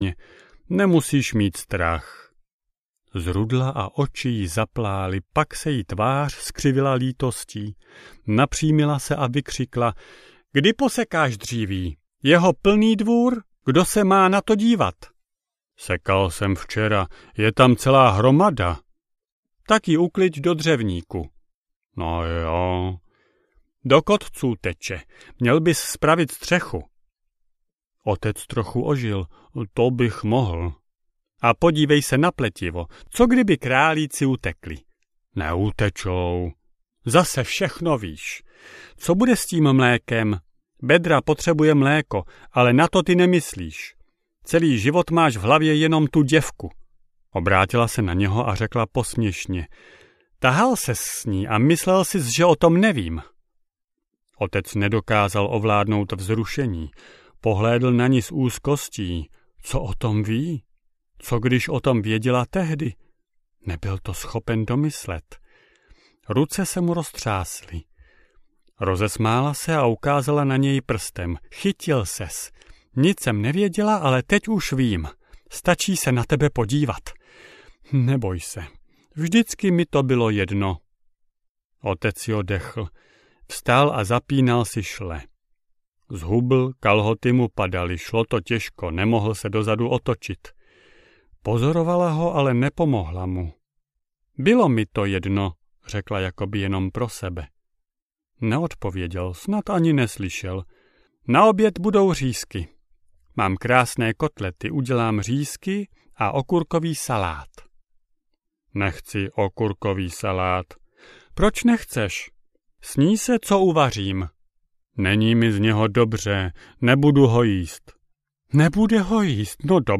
Louka audiokniha
Ukázka z knihy